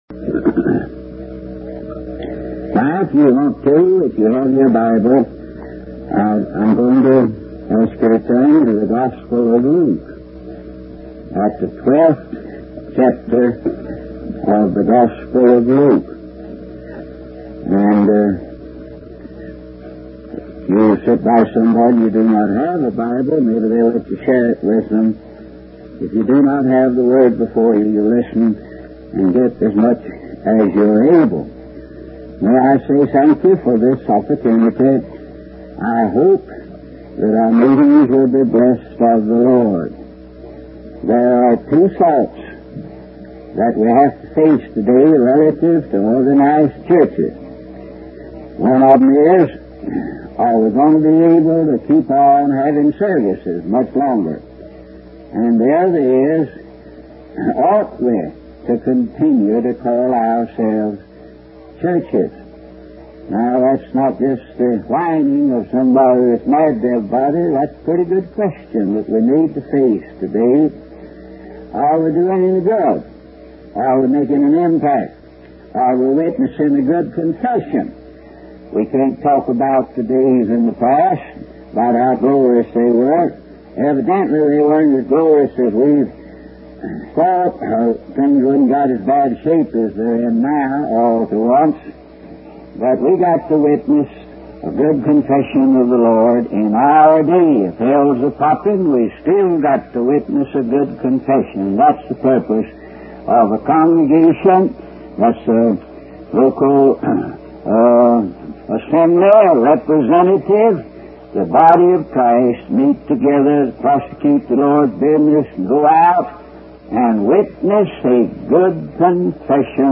In this sermon, the preacher begins by expressing his gratitude for the opportunity to speak to the congregation.